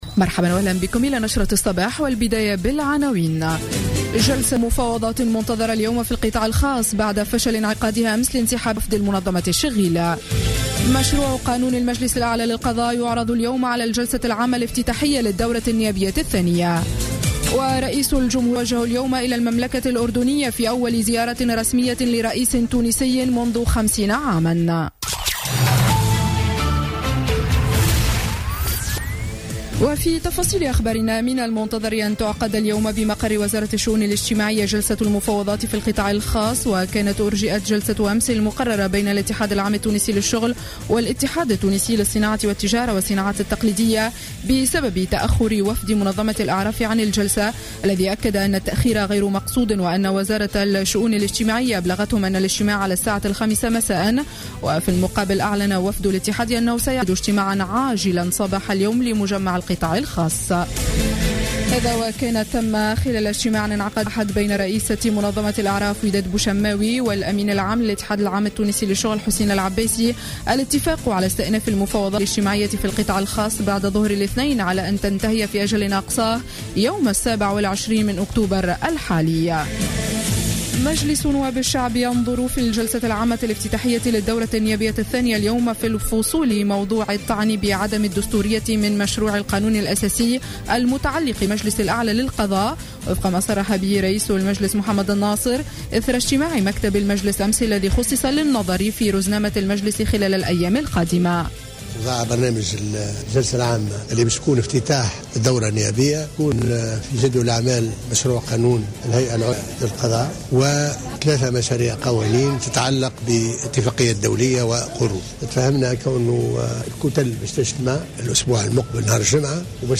نشرة أخبار السابعة صباحا ليوم الثلاثاء 20 أكتوبر 2015